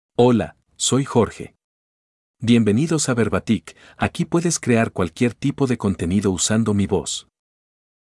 MaleSpanish (Mexico)
Voice sample
Male
Spanish (Mexico)
Jorge delivers clear pronunciation with authentic Mexico Spanish intonation, making your content sound professionally produced.